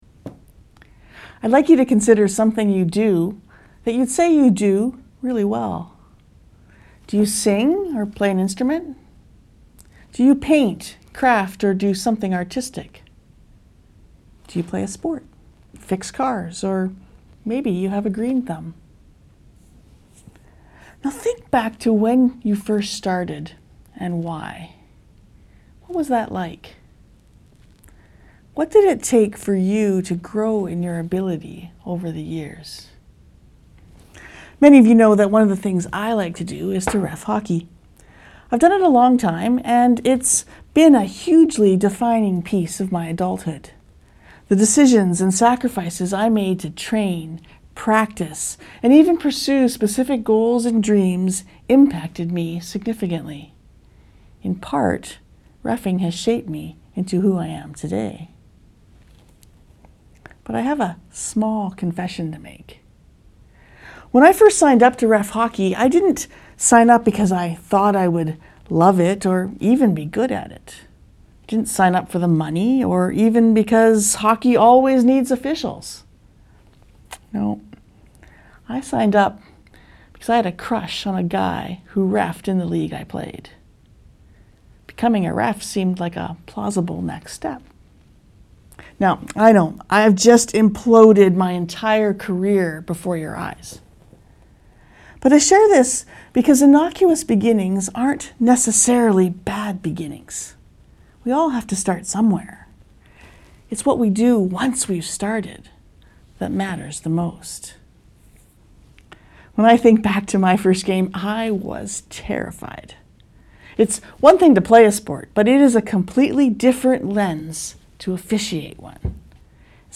SERMON NOTES We Become Like Jesus to Love Like Jesus 1.